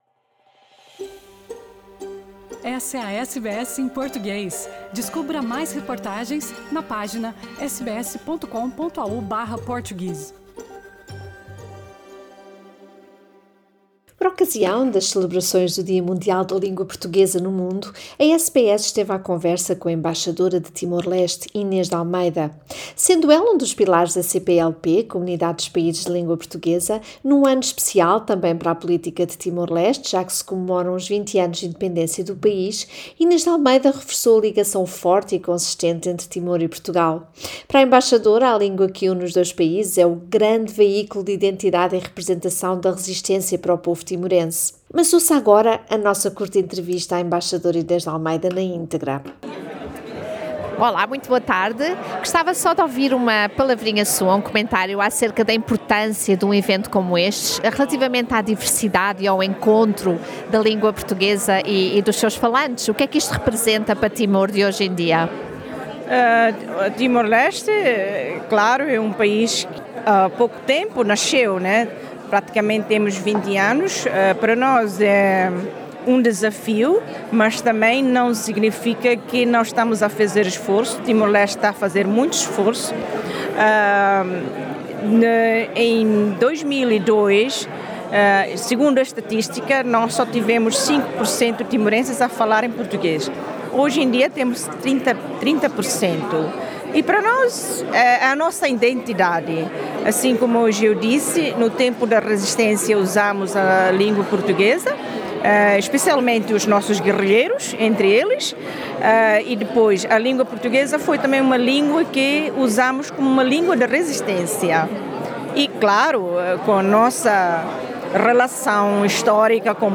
Por ocasião das celebrações do Dia Mundial da Língua portuguesa no Mundo, a SBS esteve à conversa com a embaixadora de Timor-leste, Inês de Almeida.